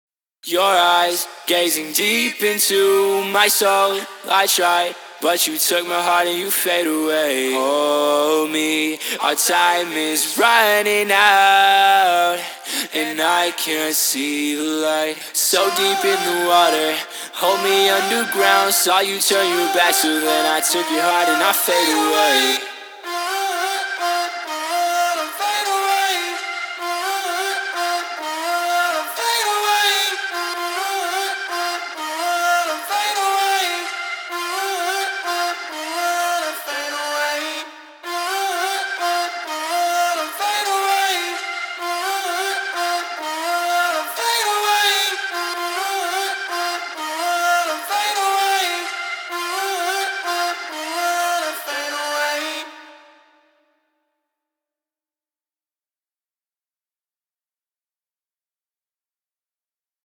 • 19 Original Acapellas Stems Sung By Me
• 7 Unique Vocal Chops
• 3 Chord Stacks
• 3 One Shots